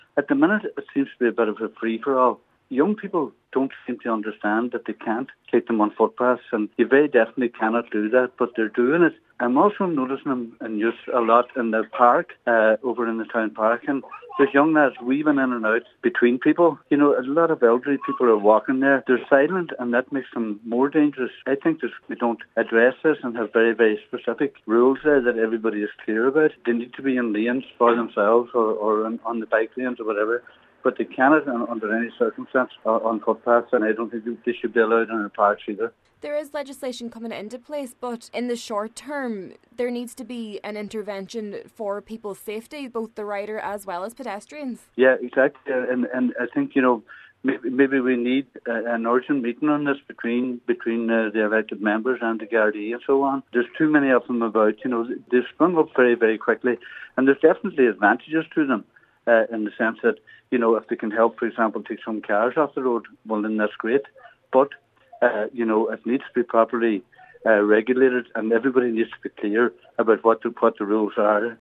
Cllr. Kavanagh suggested an urgent meeting is needed between elected members and gardaí to get short-term measures in place before legislation comes into full effect around the last quarter of the year: